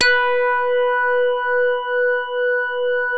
JAZZ HARD H3.wav